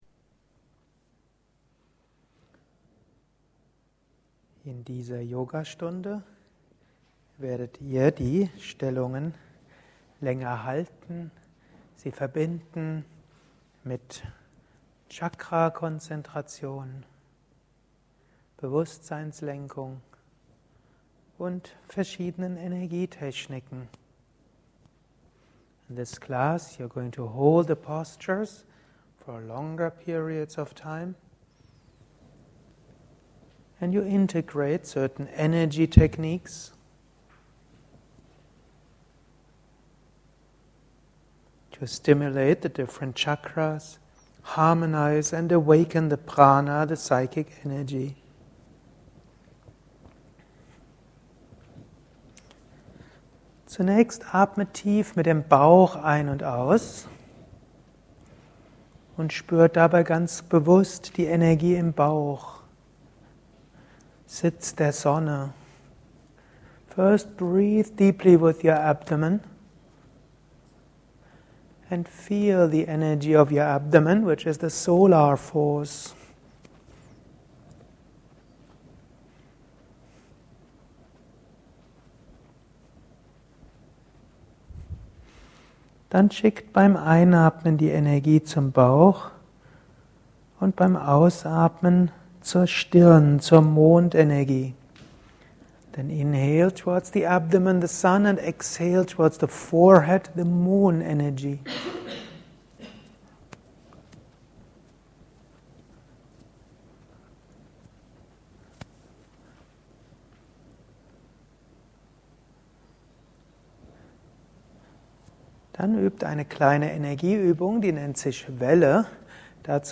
Yogastunde deutsch-englisch. Langes Halten der Stellungen mit Chakra-Konzentration.